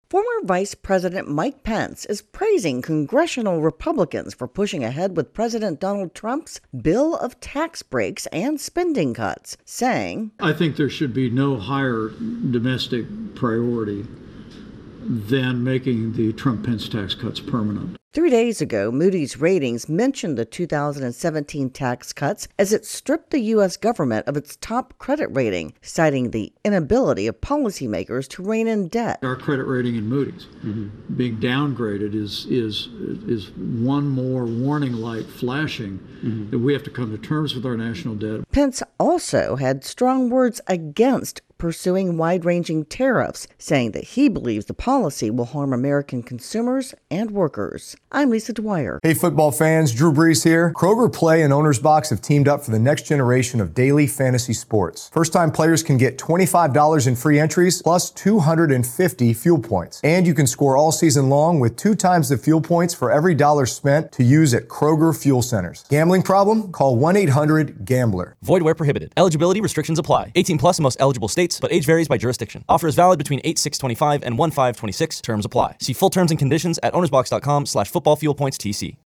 reports that at an event in North Carolina, former vice president Mike Pence spoke against broad tariffs, but praised the House for their work on the tax bill.